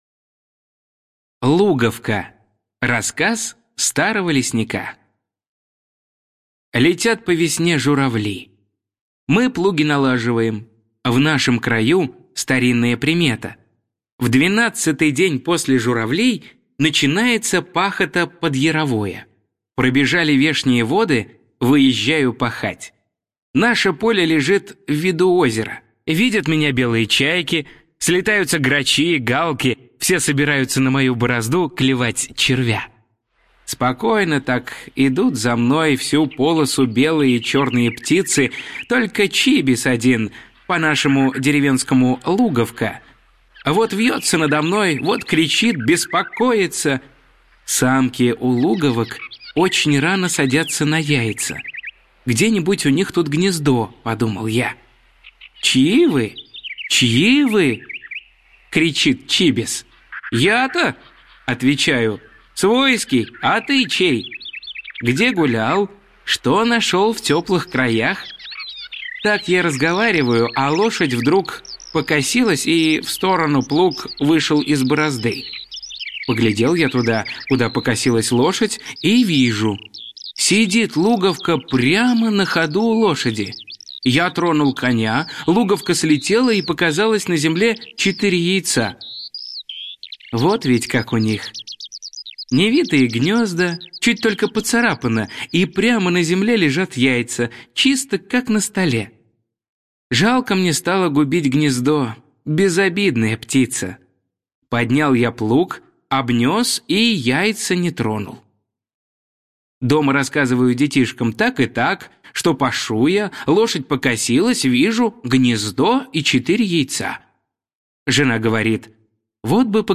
Луговка - Пришвин - слушать рассказ онлайн
Луговка - аудио рассказ Пришвина М.М. В рассказах М.Пришвина о людях и животных есть прекрасные описания лесов и полей, рек и озёр.